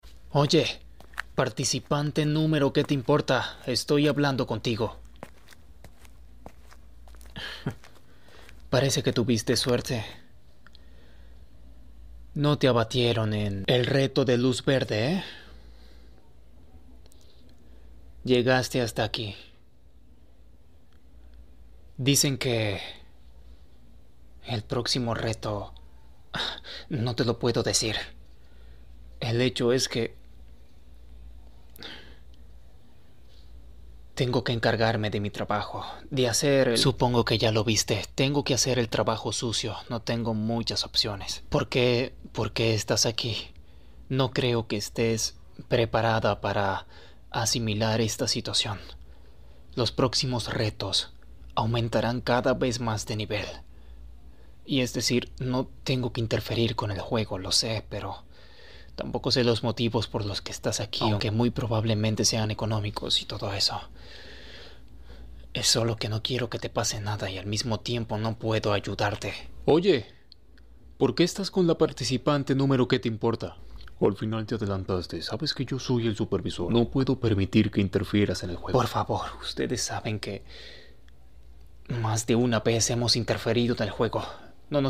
Squid Game juegos del calamar roleplay ASMR la serie